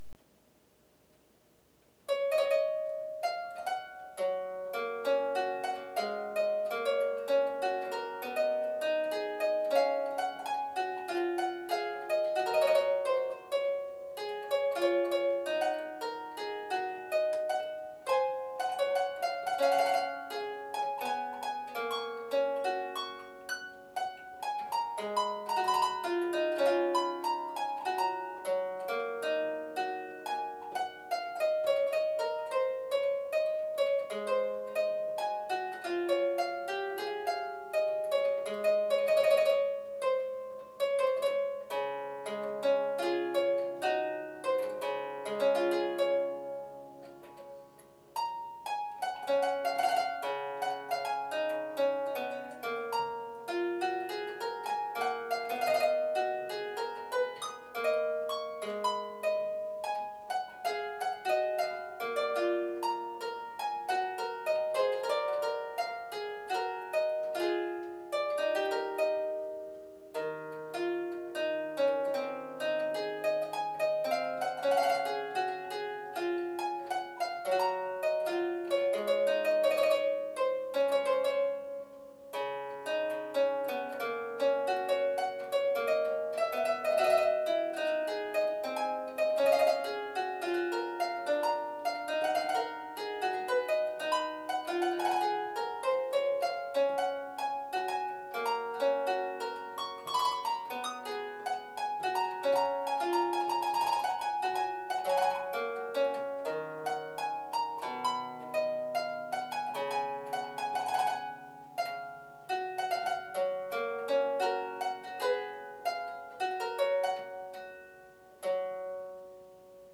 3' Ottavino Lautenwerk - 2x4' featuring an unprecedented action consisting of one rank of jacks plucking one or both choirs with single plectra.